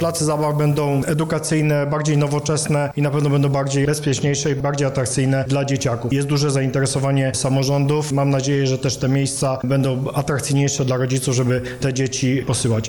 – mówi Andrzej Maj, Wicewojewoda Lubelski